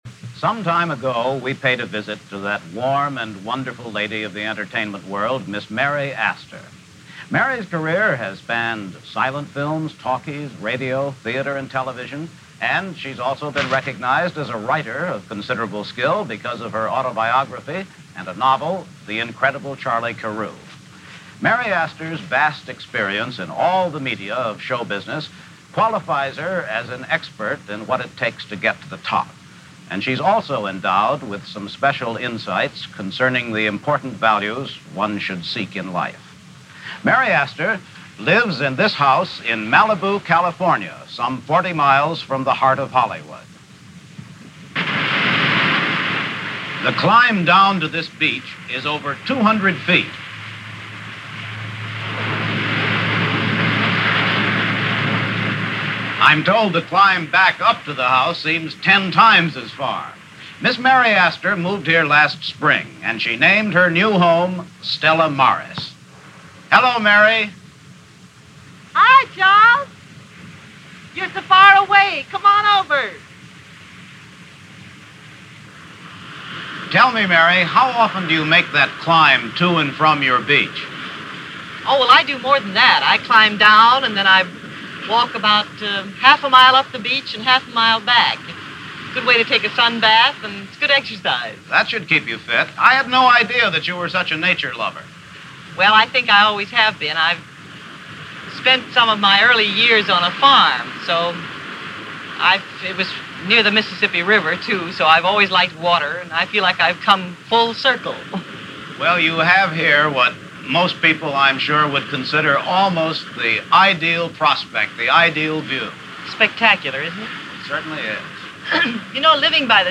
An interview with Mary Astor by CBS Reporter Charles Collingwood for Person To Person on August 25, 1961.